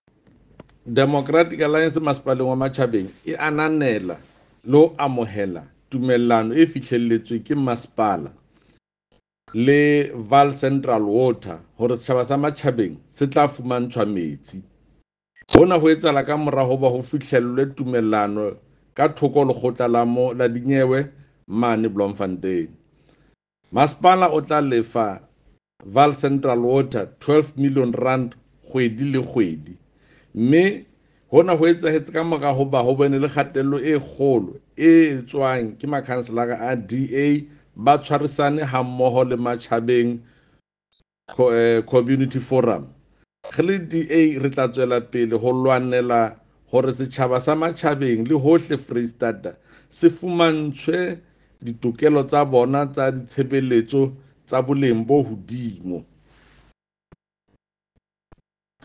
Sesotho by Jafta Mokoena MPL.